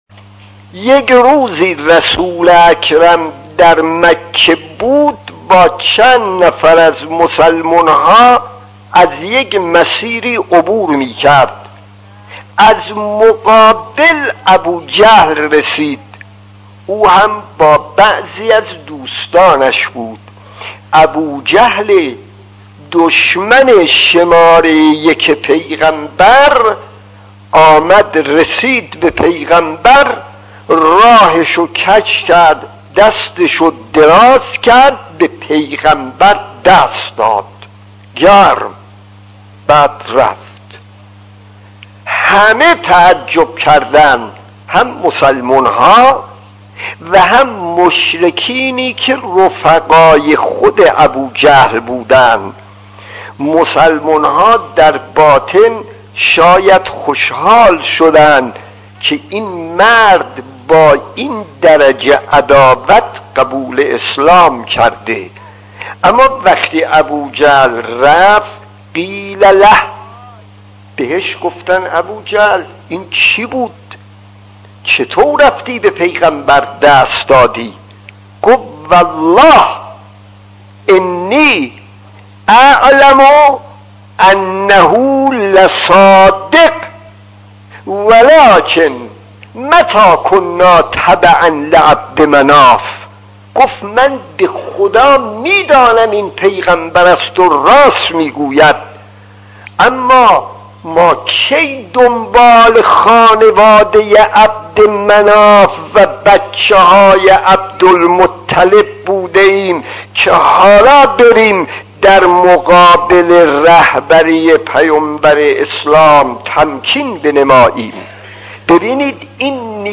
داستان 43 : پیامبر و ابوجهل خطیب: استاد فلسفی مدت زمان: 00:02:12